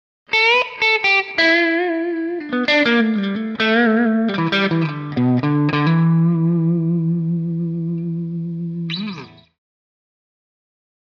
Electric Guitar
Blues Guitar - Short Solo 3